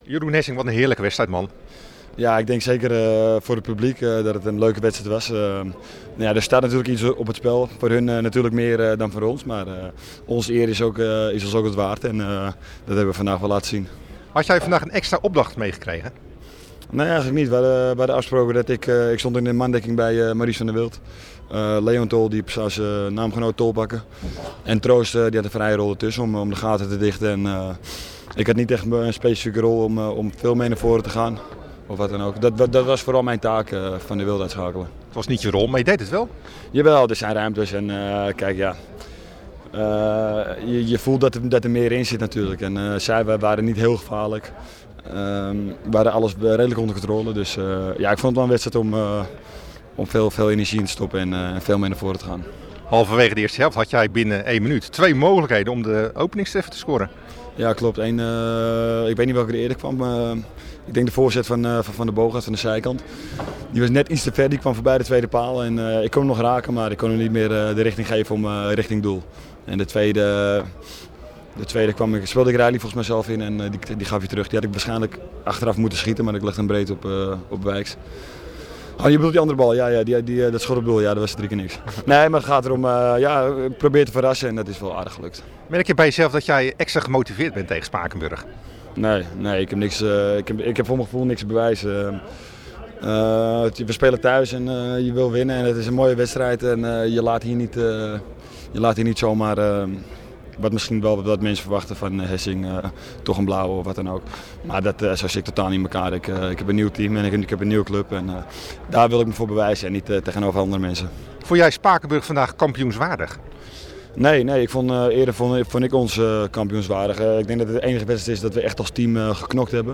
VIDEO: Nabeschouwing Rijnsburgse Boys – Spakenburg